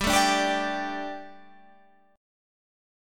Gsus2 chord